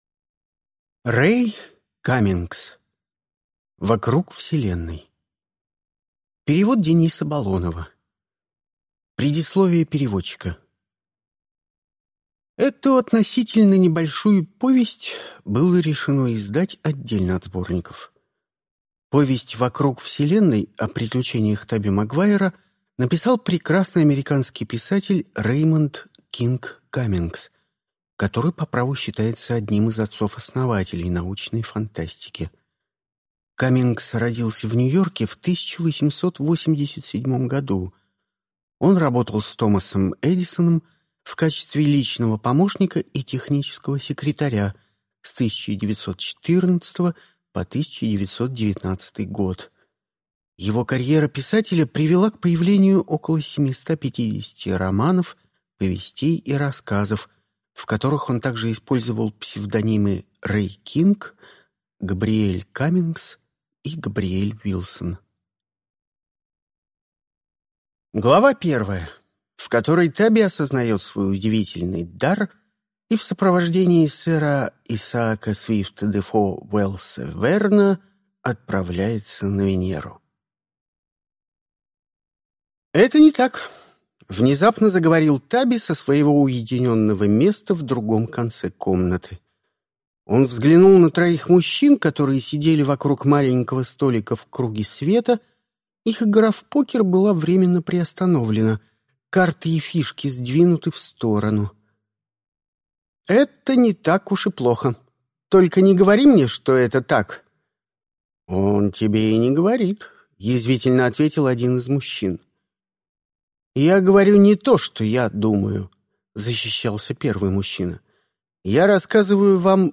Aудиокнига Вокруг вселенной